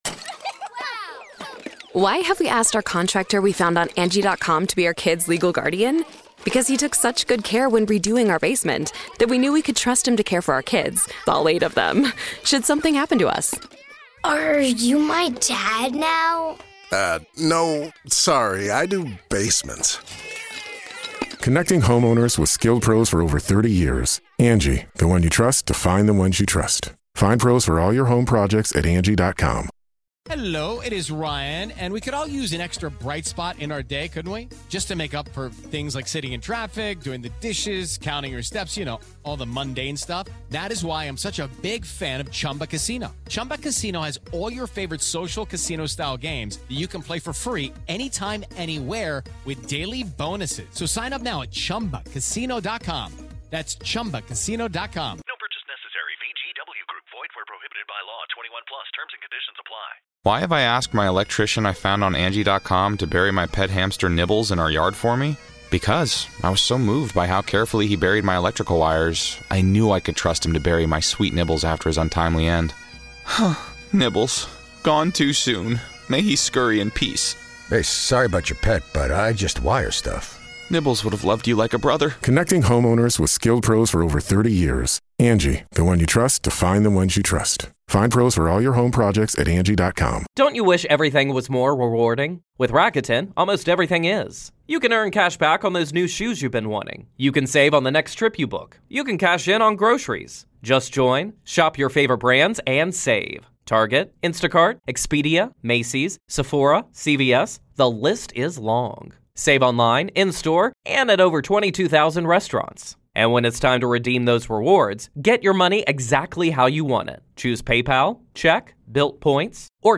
This powerful motivational speech by Daily Motivations is about one thing-taking control of your thoughts before they control your life. Built on discipline, consistency, and mental toughness, this video pushes you to eliminate distractions, break negative patterns, and stay focused on what actually matters.